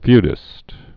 (fydĭst)